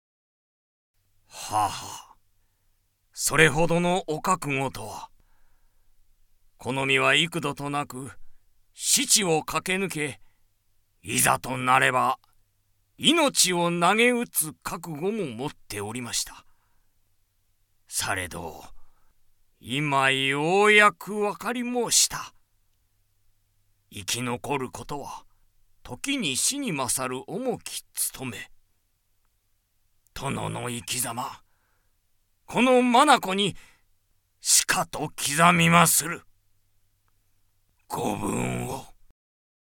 ボイスサンプル
台詞